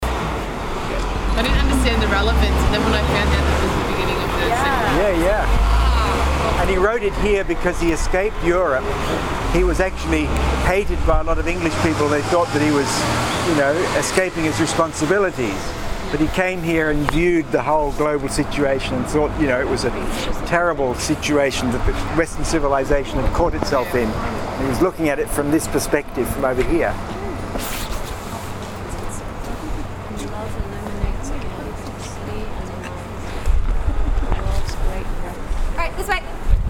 Here is where W.H. Auden wrote his amazing poem “September 1, 1939”, on the eve of World War 2. Here is our guide: